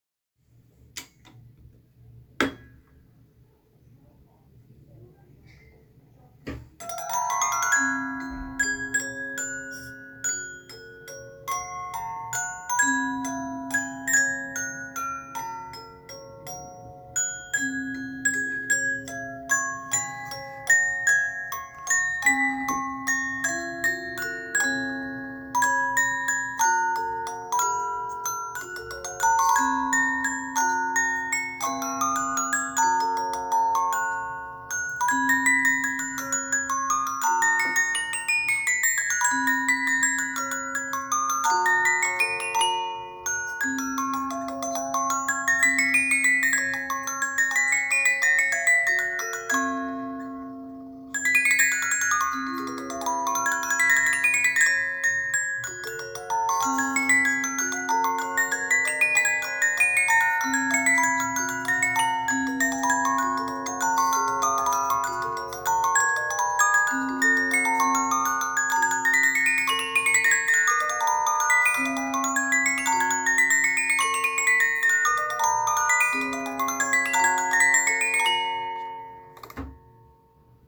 I Brani del grande CARILLON
Il carillon posto nella base è infatti composto da un pettine a 16 blocchi e 61 segmenti e permette di eseguire con grande ricchezza melodica le due arie presenti sul rullo, della durata di un minuto e 20 ciascuna.
Carillon1.m4a